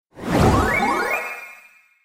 レベルアップの瞬間を感じる8ビットの効果音。